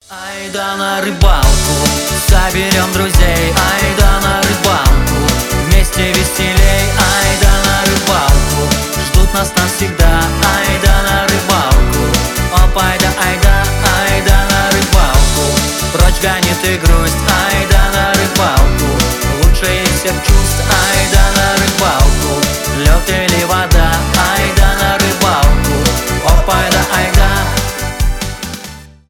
кантри , позитивные